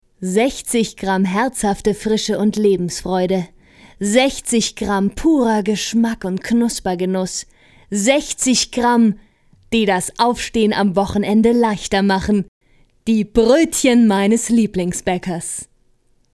wandelbaren, dynamischen Stimme
Kein Dialekt
Sprechprobe: Werbung (Muttersprache):